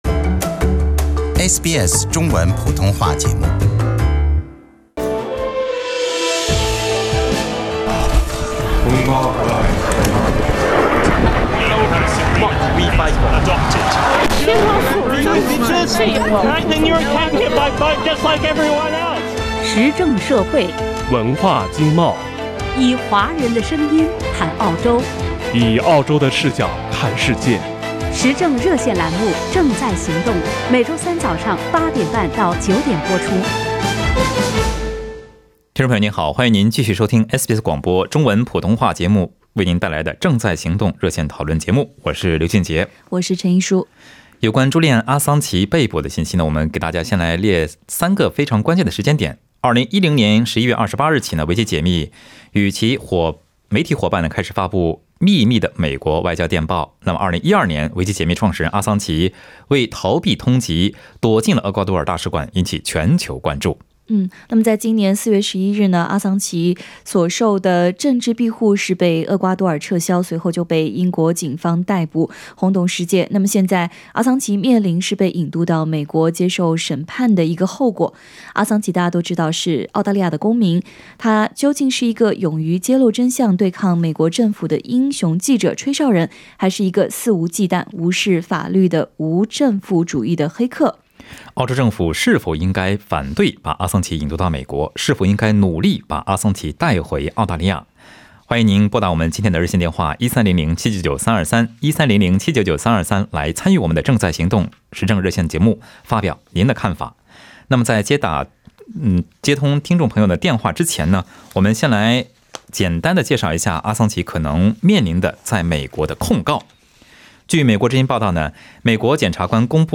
本期《正在行动》节目中，有听众打入电话表示，澳大利亚政府应该避免让阿桑奇被引渡到美国，应该把他带回澳大利亚。